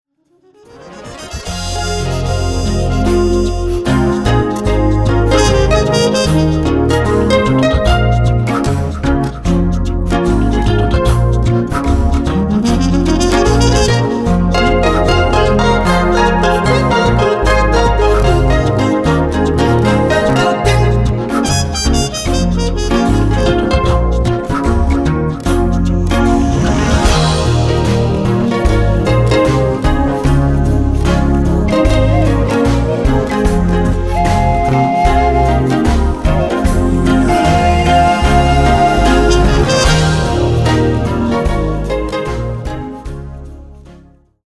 avoharp, breath & vocal percussion, Greek double flute
trumpet